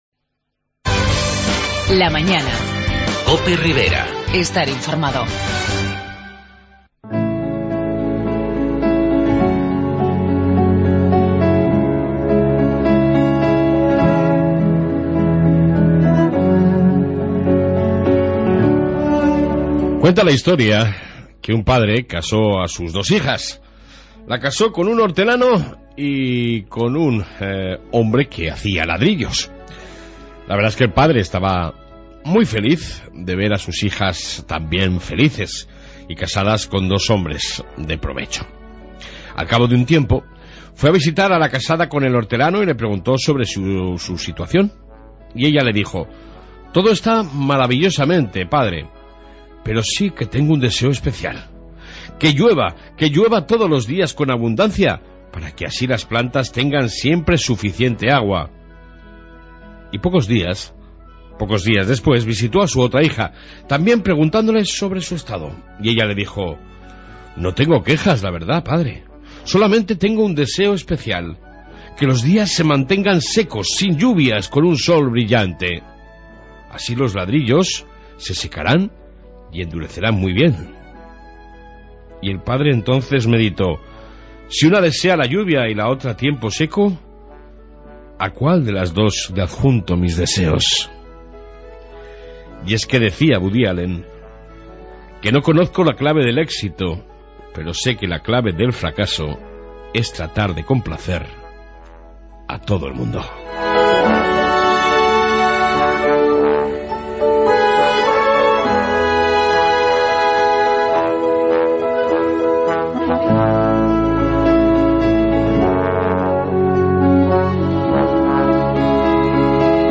AUDIO: En esta 1 parte Reflexión diaria, Informe Policía Municipal y entrevista con el nuevo Presidente de la Mancomunidad Tomás Aguado